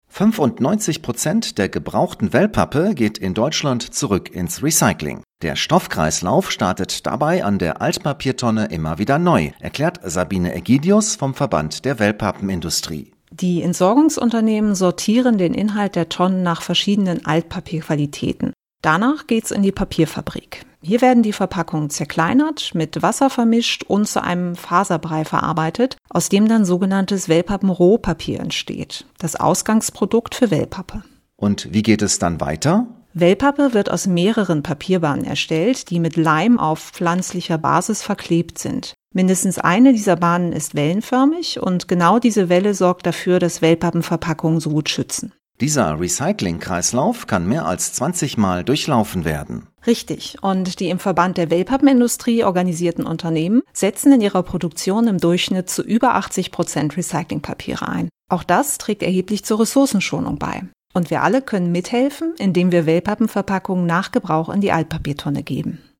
rts-beitrag-recycling.mp3